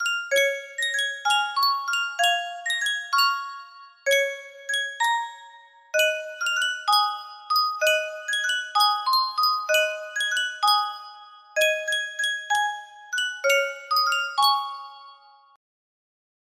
Yunsheng Music Box - Blow the Man Down 4758 music box melody
Full range 60